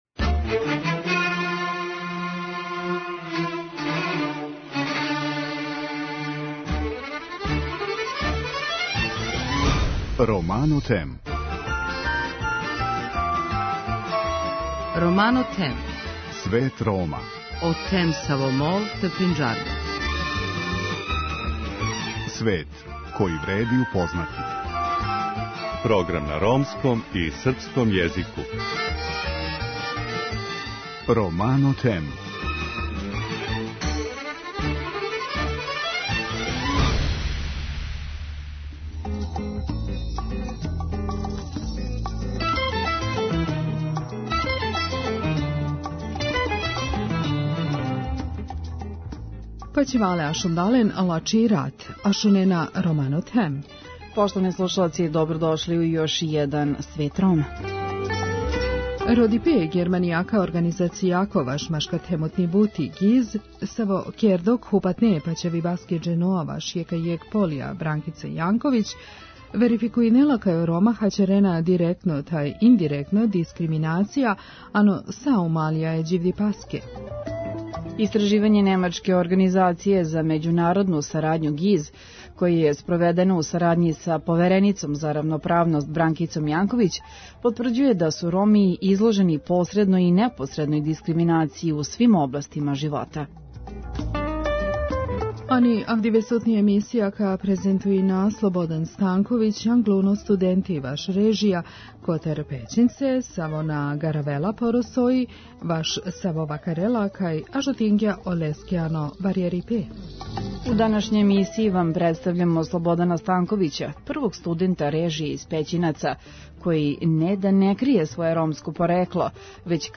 Емисија свакодневно доноси најважније вести из земље и света на ромском и српском језику.